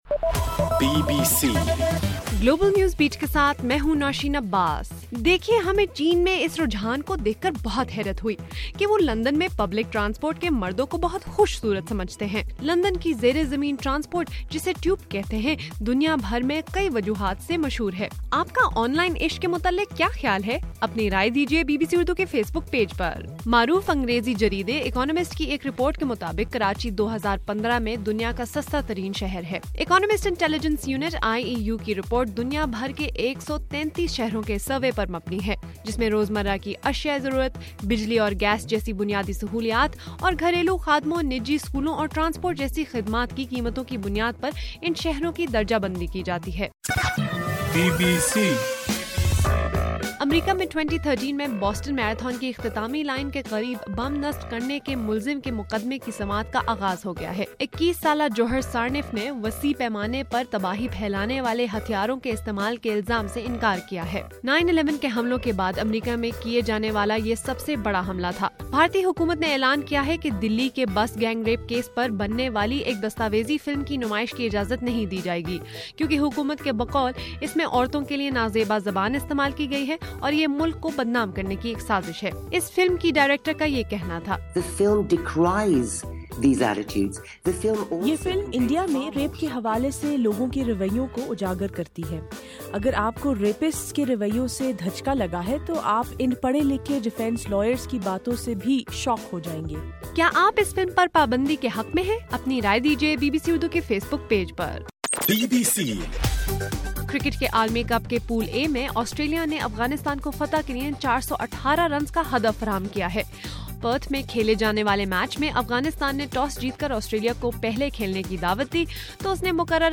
مارچ 4: رات 11 بجے کا گلوبل نیوز بیٹ بُلیٹن